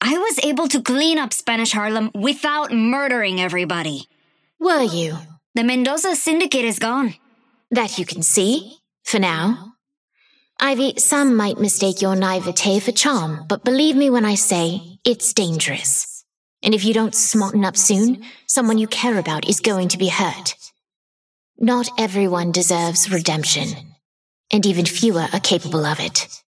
Ivy and Vindicta conversation 3